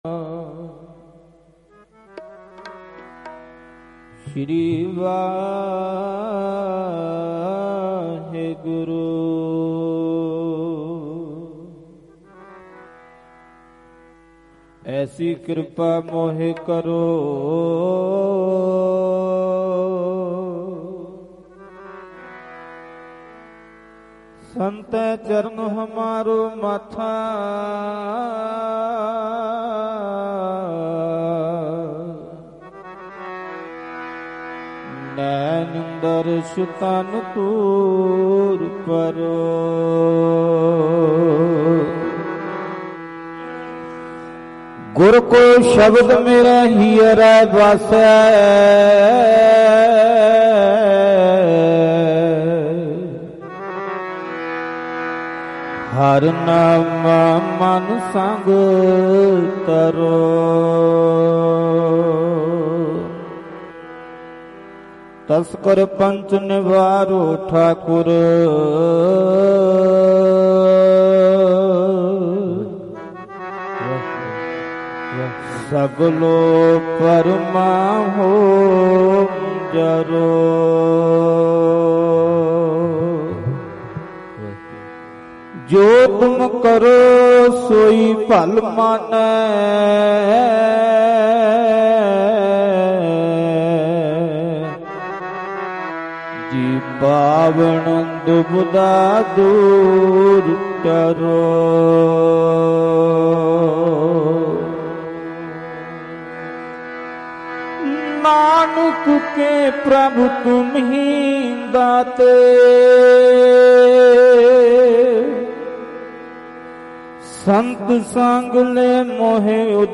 Live Gurmat Samagam Bainhar Sri Anandpur Sahib 11 Nov 2025 Dhadrian Wale | DhadrianWale Diwan Audios mp3 downloads gurbani songs
Mp3 Diwan Audio by Bhai Ranjit Singh Ji khalsa Dhadrian wale